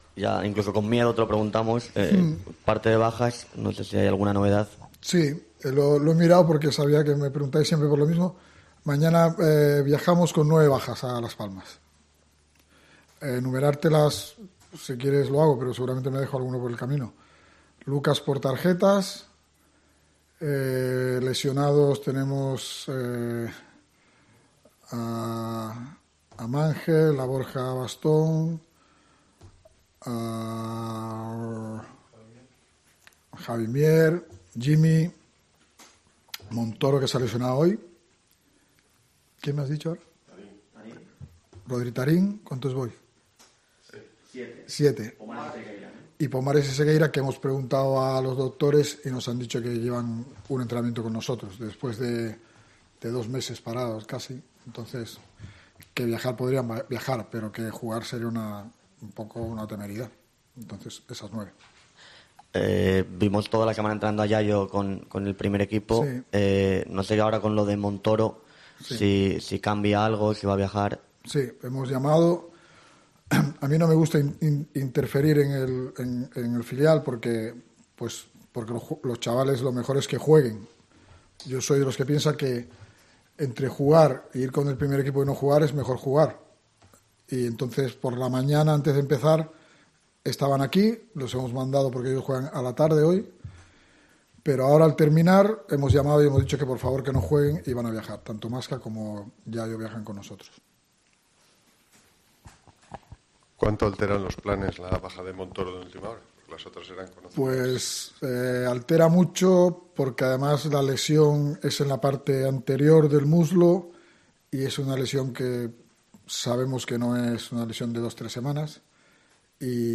Rueda de prensa Cervera (previa Las Palmas)